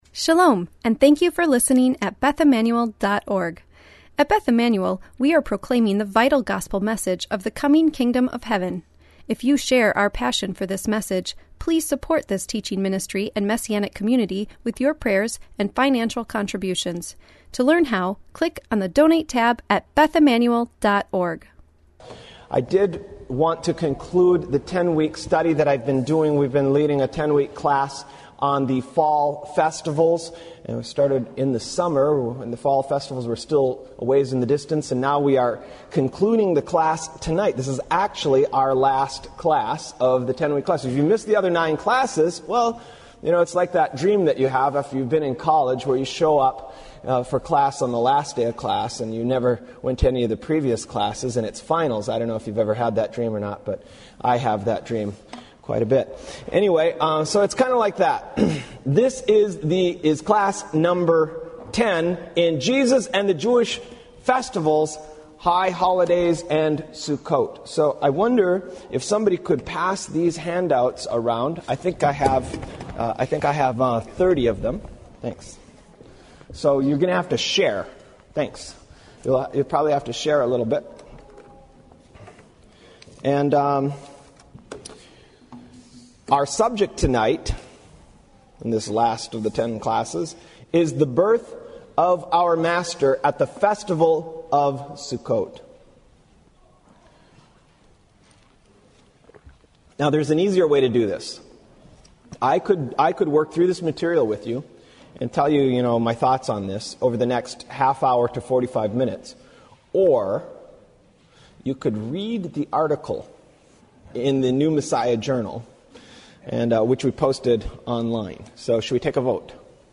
Why do we celebrate the birth of Yeshua on the first day of the festival of Sukkot, and why do we remember the day of the giving of his name on the eighth day of Sukkot? Listen to this teaching to learn about the literary evidence that points to early Jewish disciples of Yeshua celebrating the festival of Sukkot as the season of our Master's birth.